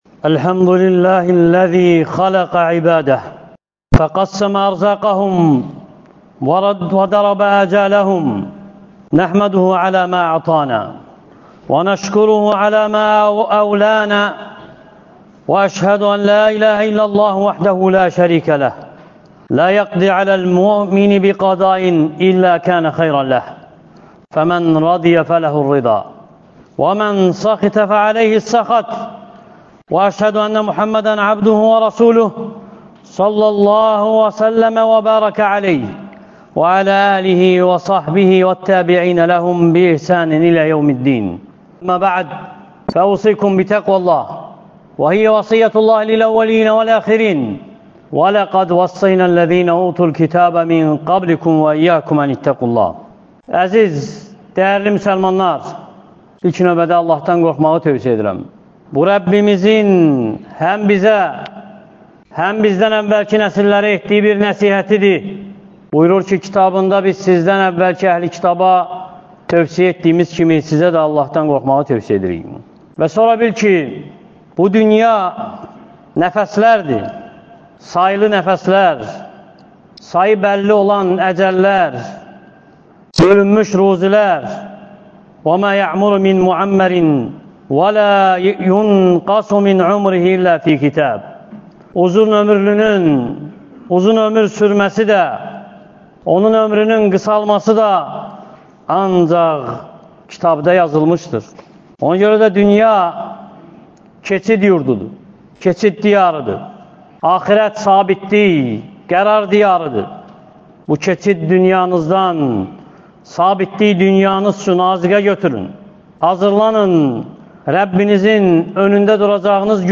Ləzzətləri parça-parça edən ölümü xatırlayın (Cümə xütbəsi — 02.08.2024) | Əbu Bəkr məscidi